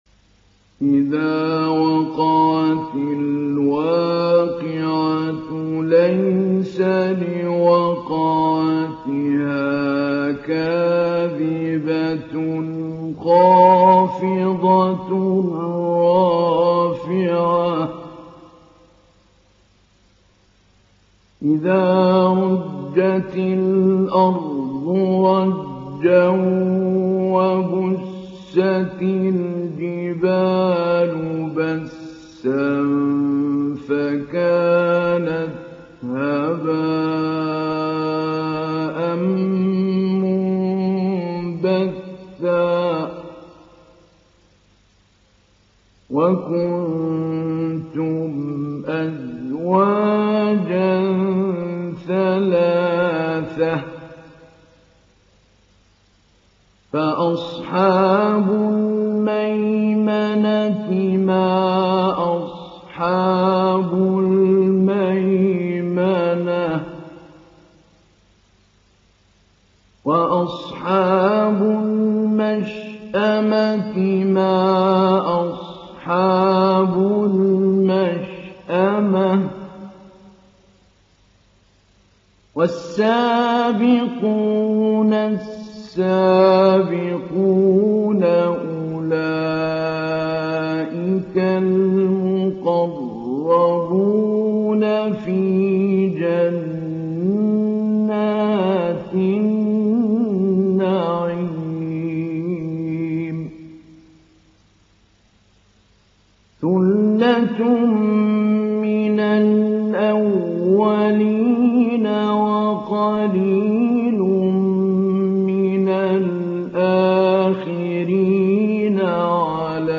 دانلود سوره الواقعه mp3 محمود علي البنا مجود روایت حفص از عاصم, قرآن را دانلود کنید و گوش کن mp3 ، لینک مستقیم کامل
دانلود سوره الواقعه محمود علي البنا مجود